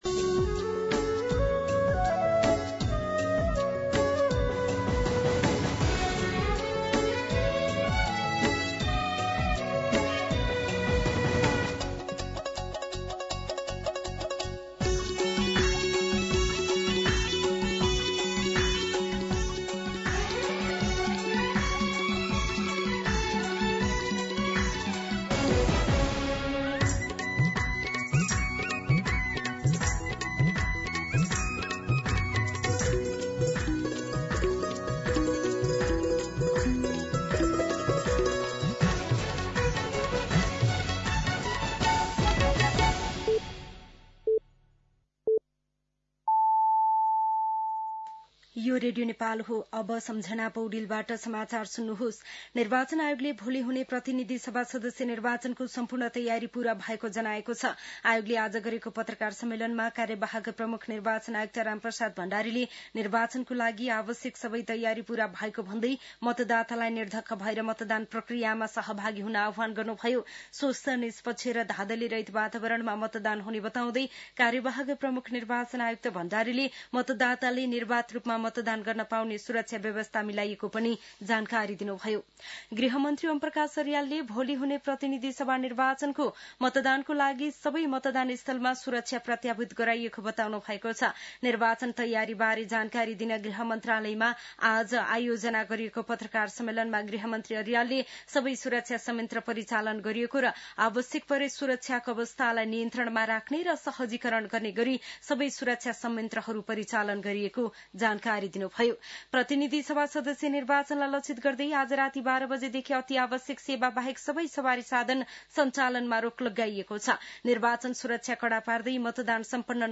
दिउँसो ४ बजेको नेपाली समाचार : २० फागुन , २०८२
4pm-News-20.mp3